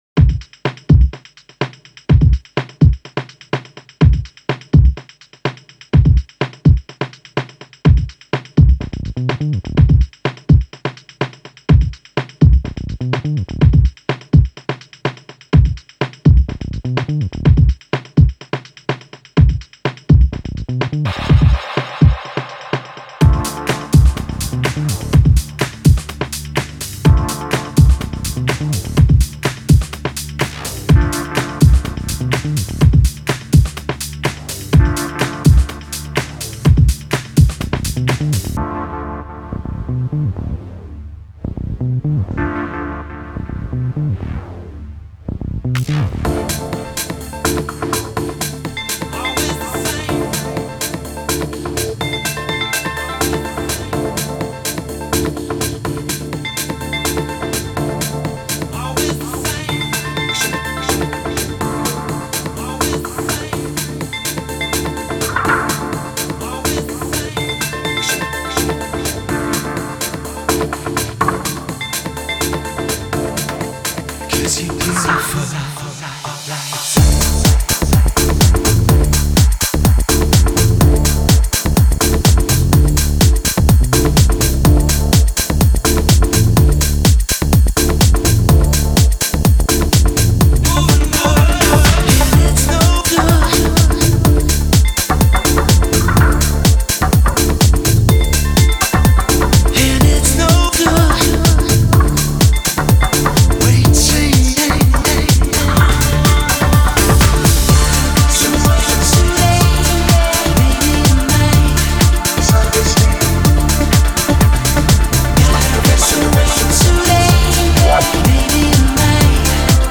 популярный британский певец.